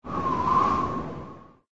SZ_TB_wind_1.ogg